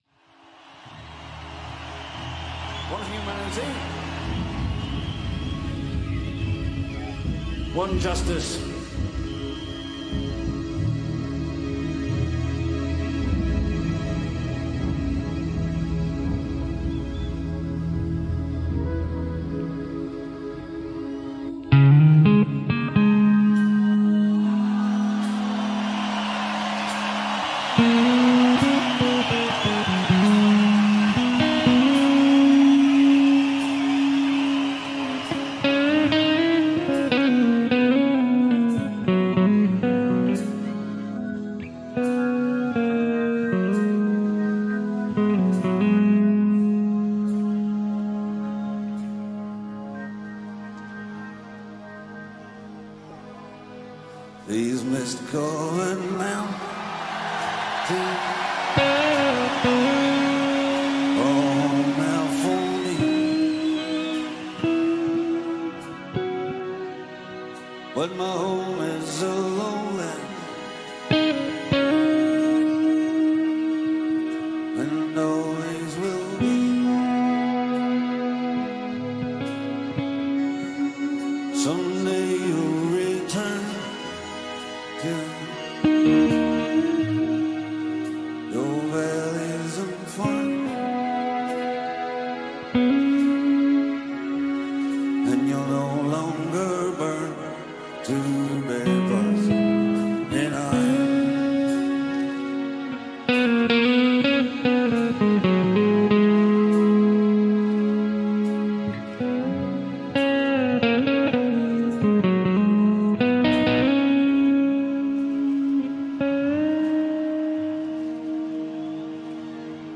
Entschleunigtes Spiel - mit etwas Einfluss einer deutschen Band, die nur bei völliger Dunkelheit auftritt. Wobei ich dagegen hier doch noch etwas zu schnell sein könnte. your_browser_is_not_able_to_play_this_audio Zum Sound: Squier FSR Jazzmaster mit Hermanns-Guitar Hals, roasted, und - frischen - GHS Boomers 12-52 Saiten. Zu hören ist der Hals Pickup, Jess Loureiro "Surf".